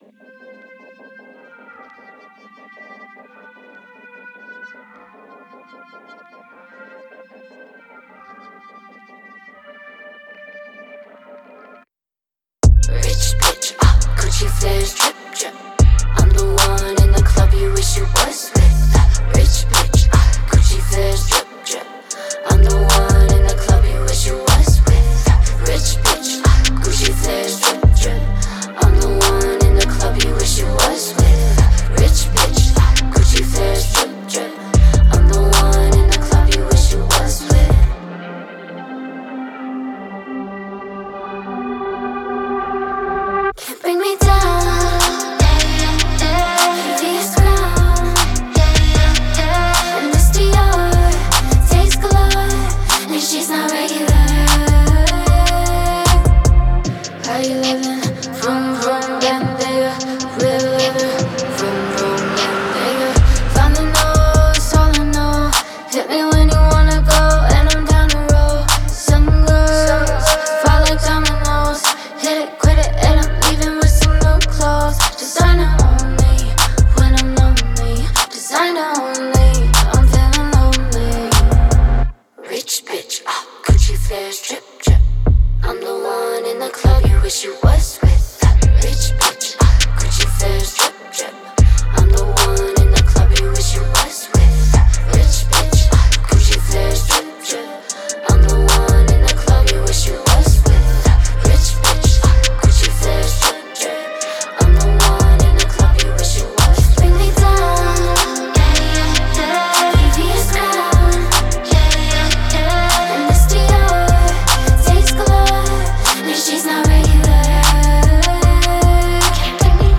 это энергичная и зажигательная песня в стиле хип-хоп